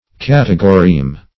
categoreme - definition of categoreme - synonyms, pronunciation, spelling from Free Dictionary